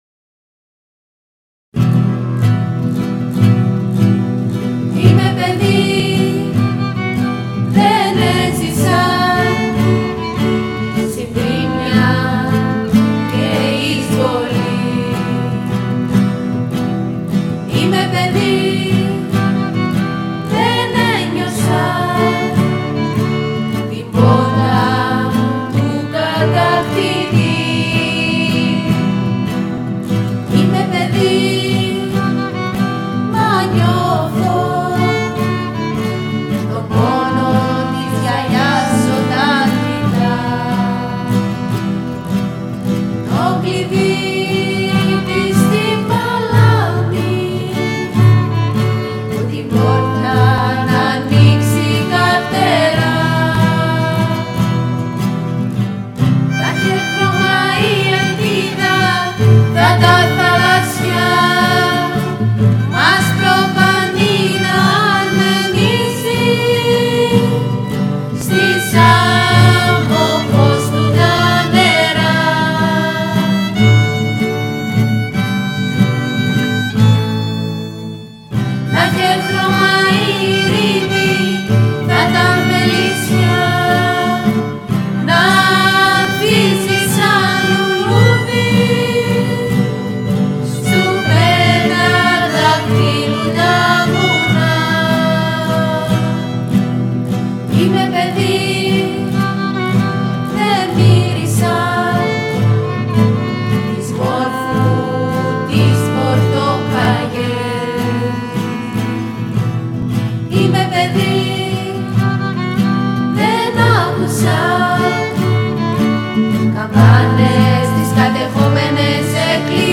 Τραγούδι
Βιολί
Κιθάρα